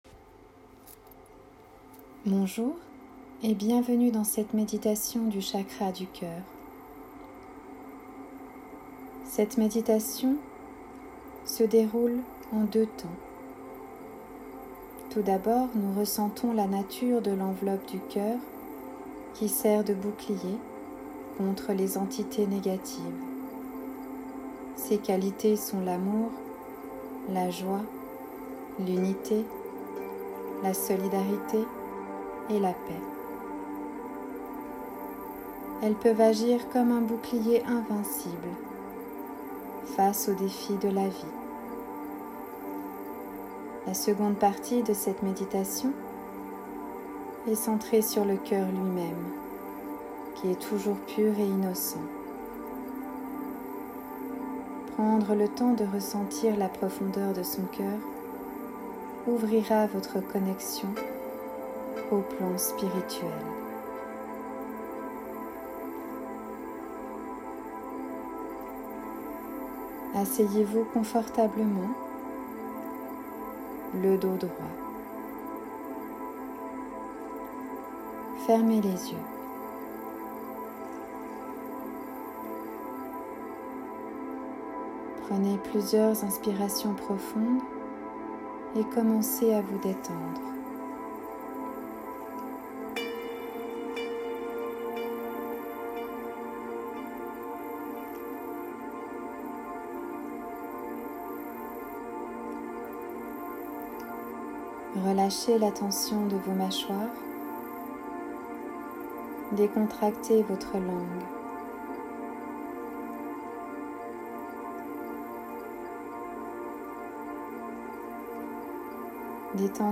Méditation - chakra du coeur
meditation-chakra-coeur-mai2021.mp3